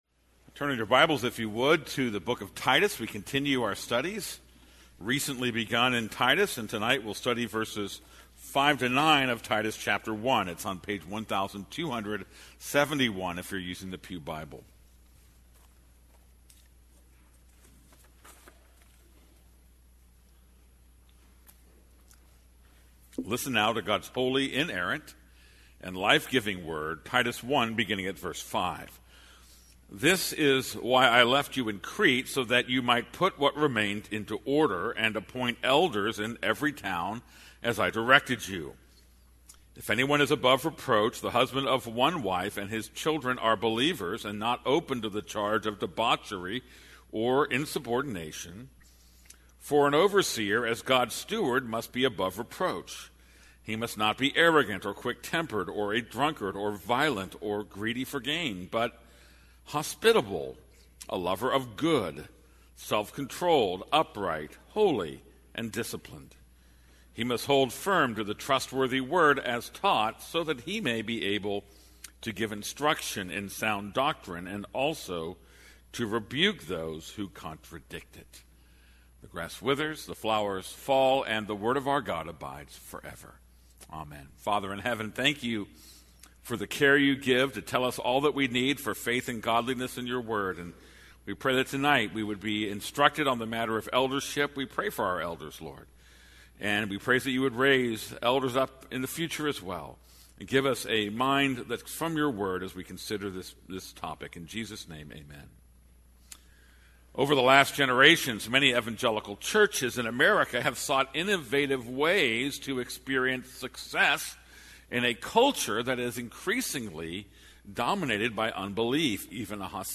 This is a sermon on Titus 1:5-11.